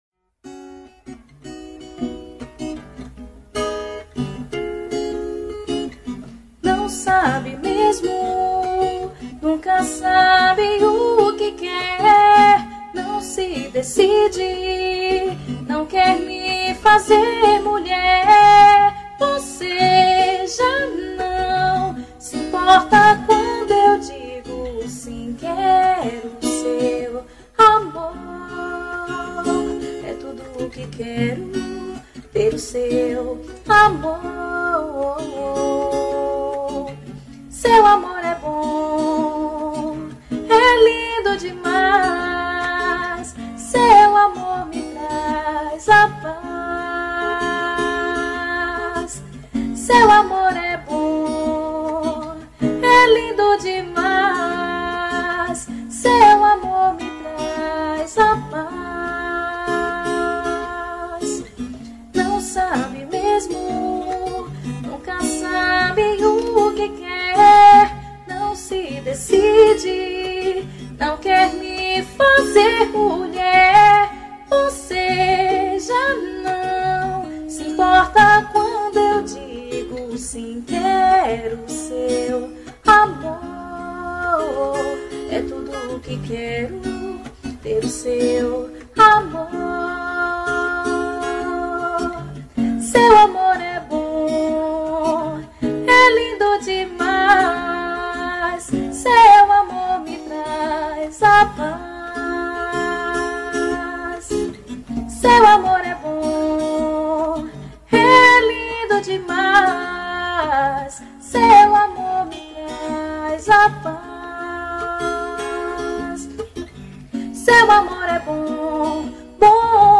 Composição: voz e violão.